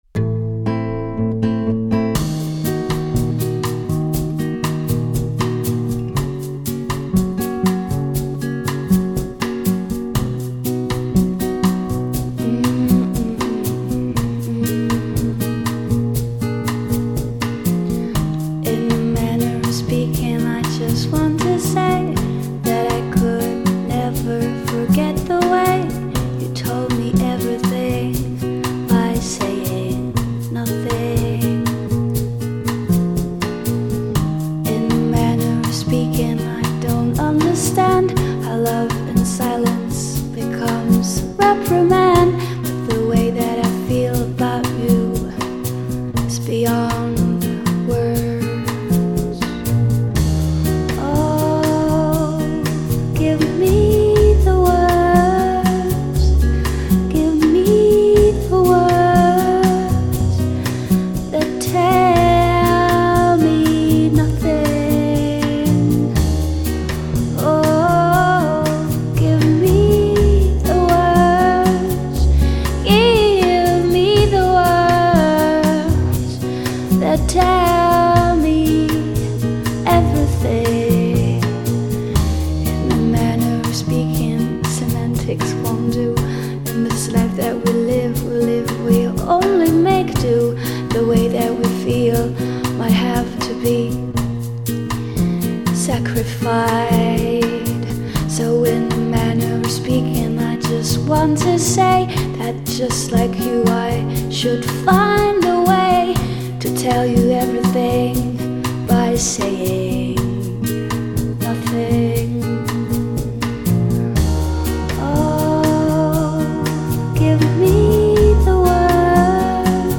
Bossa Nova covers
New Wave Bossa Nova